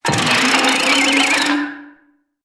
audio: Converted sound effects